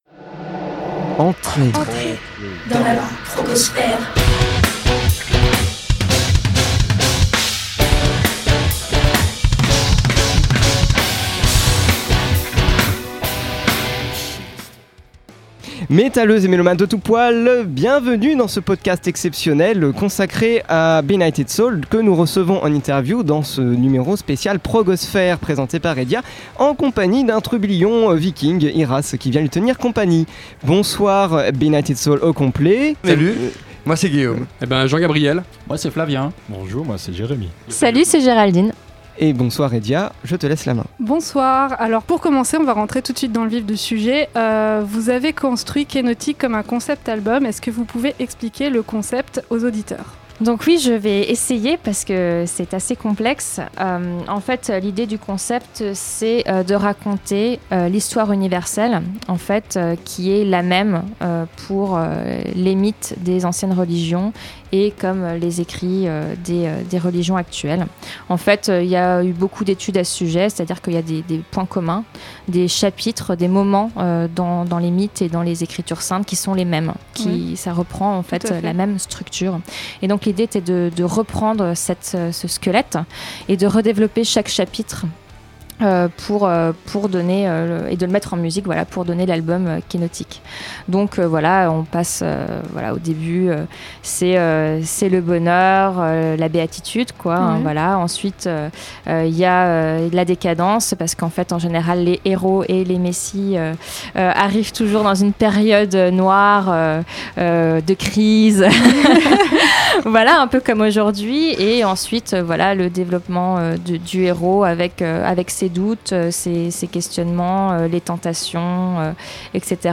Il y a quelques jour, à l’occasion de la release de Kenotic le dernier album du groupe BENIGHTED SOUL, nous t’avons concocté une spéciale "Progosphère" : le groupe au grand complet a eu la gentillesse de venir nous rendre une petite visite dans les studios de Radio Campus Paris et nous avons pu leur poser toutes les questions qui nous passaient par la tête.